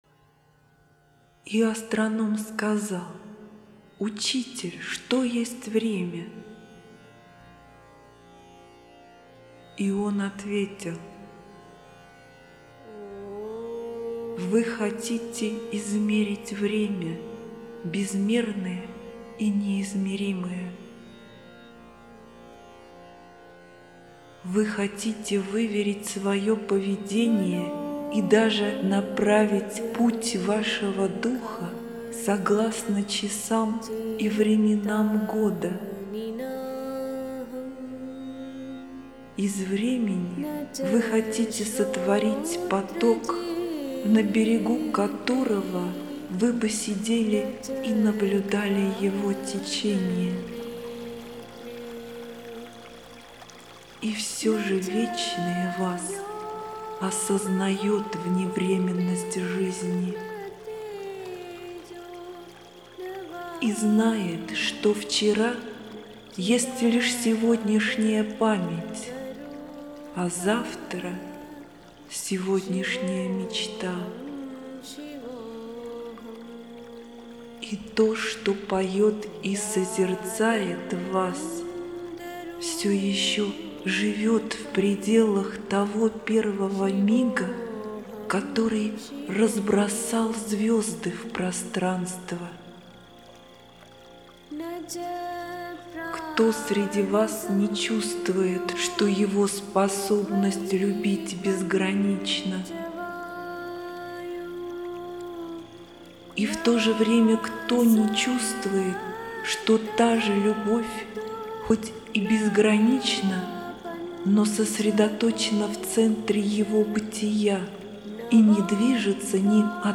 Аудио-книга "Пророк - Джебран Халиль Джебран"
Формат Аудиокнига MP3, битрейт 320, 1CD,